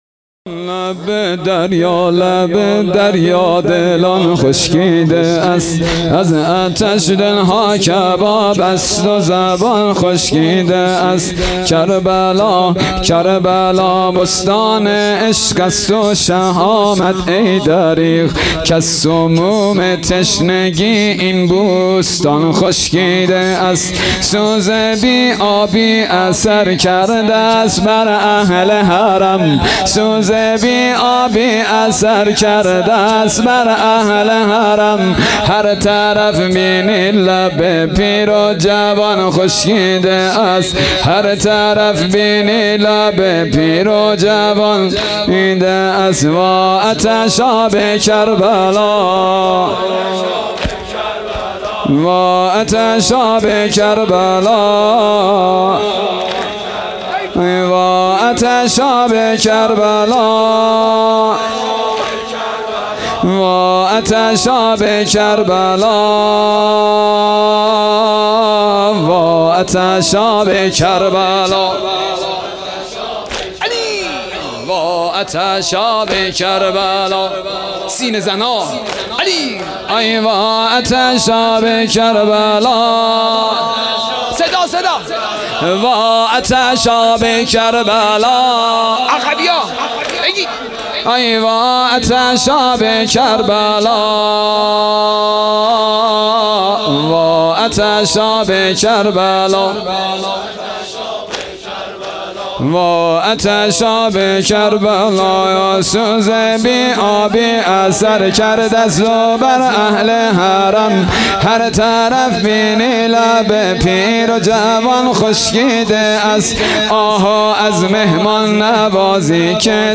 شب هفتم محرم الحرام 1443